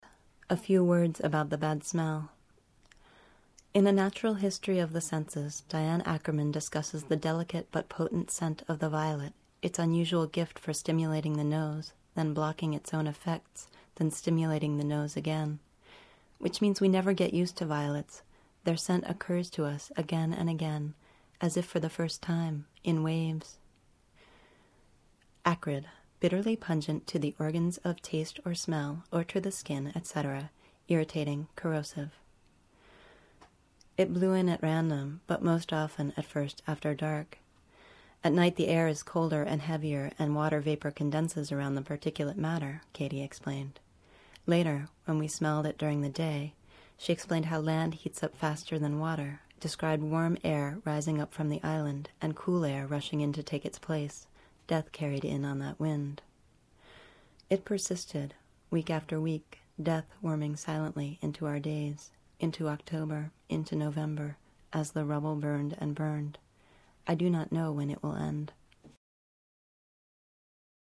We recorded these poems shortly after the events discribed.